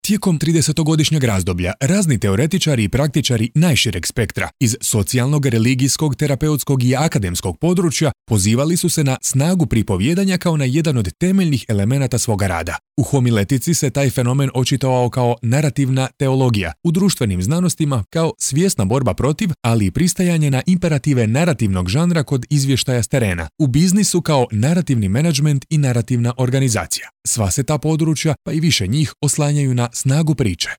Croatian voice over talent with positive and pleasant voice and a neutral accent with over 10 years of experience in broadcasting.
Kein Dialekt
Sprechprobe: Sonstiges (Muttersprache):